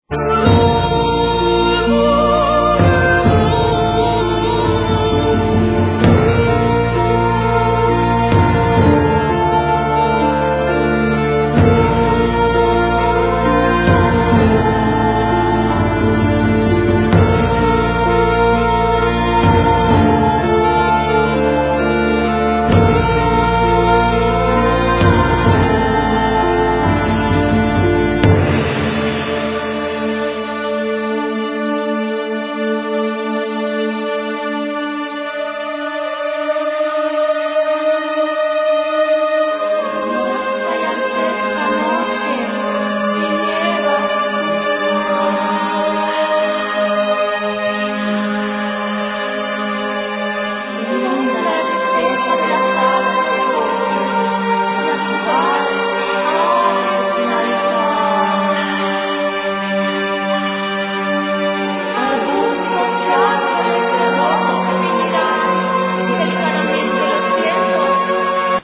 Voice, Percussions, Flute
Guitars
Bass, Drums, Voice
Whispers, Chimes, Bells